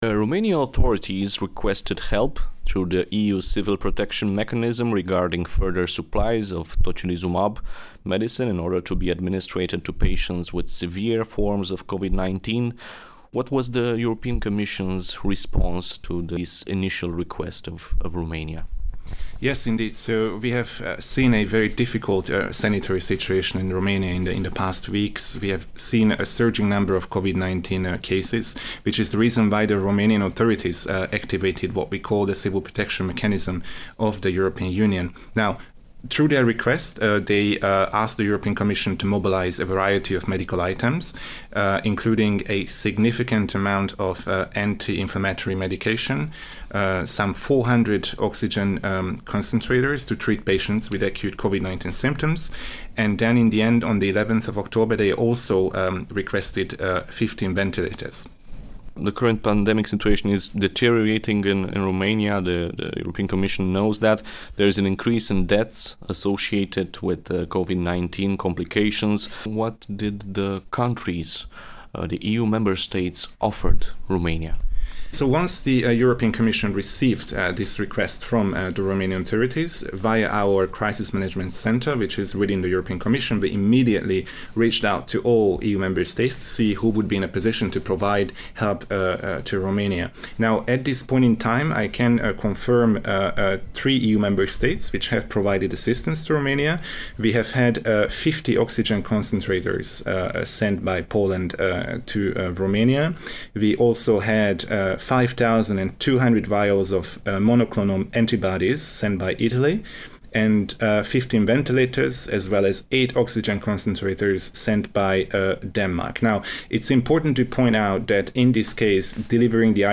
Interview (ENGLISH VERSION):